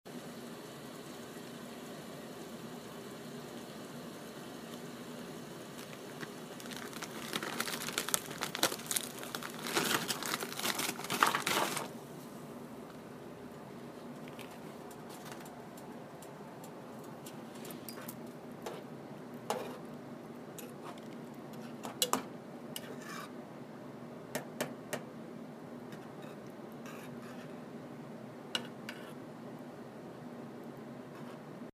Tortellini
Stuyvesant hall kitchen, 8:40 PM, 3/16
Water boiling in a pot, pouring frozen pasta in, water splashing, stirring with a fork.